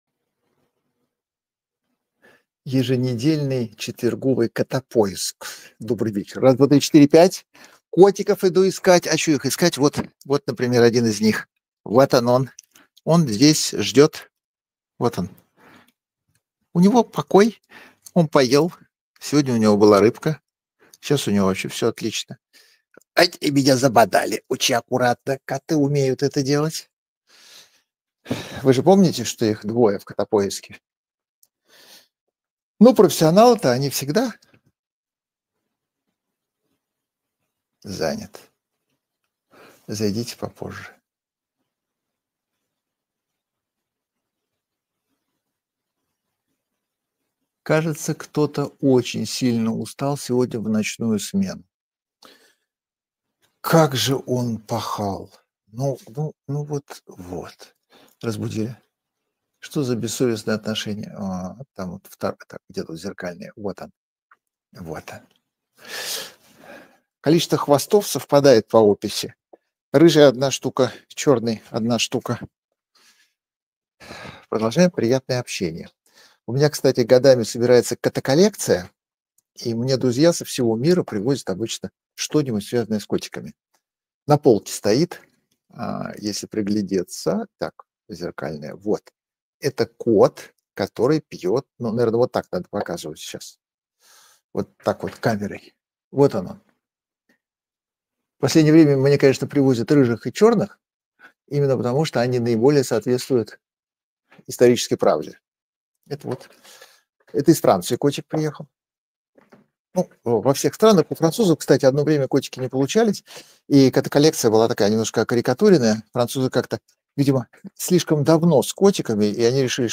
Прямая трансляция